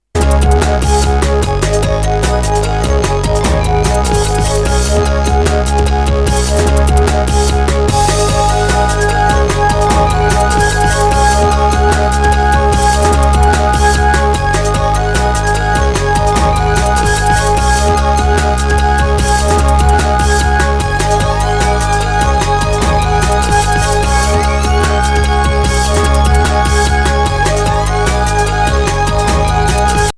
Breakbeat / Progressive House / Techno Lp Reissue